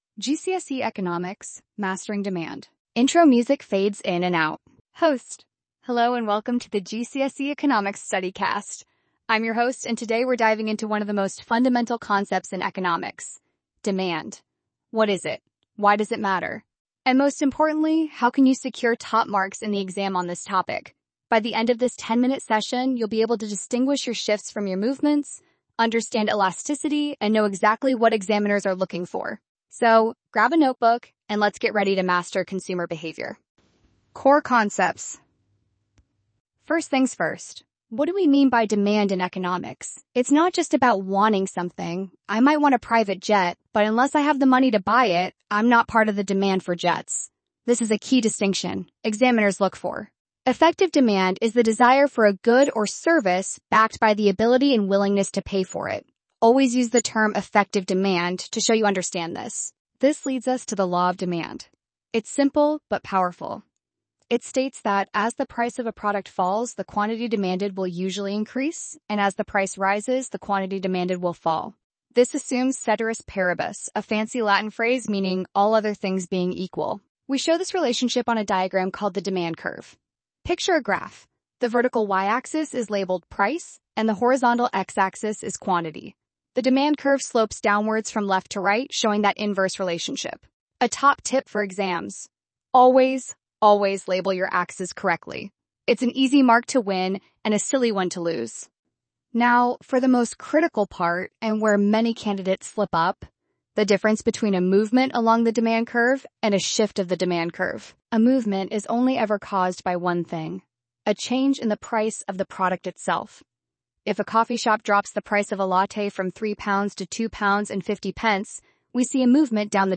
Listen to our expert tutor break down the topic of Demand.